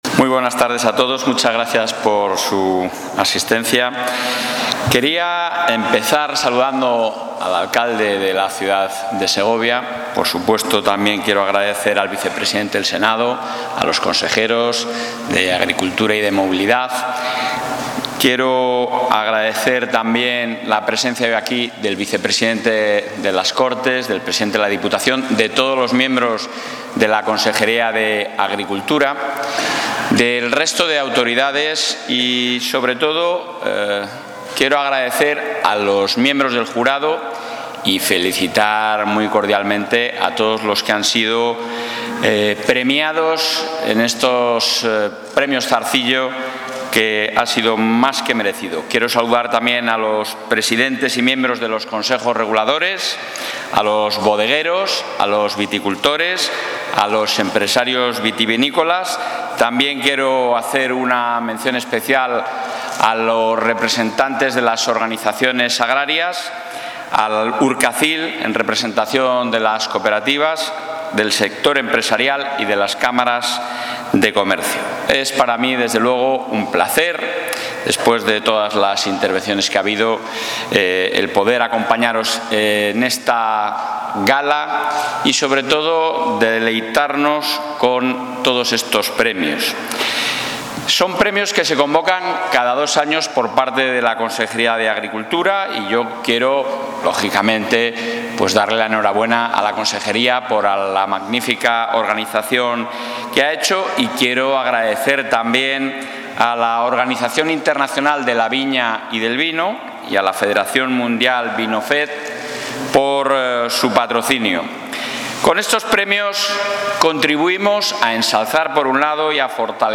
El presidente de la Junta de Castilla y León, Alfonso Fernández Mañueco, ha participado hoy, en Segovia, en la XX gala de...
Intervención del presidente.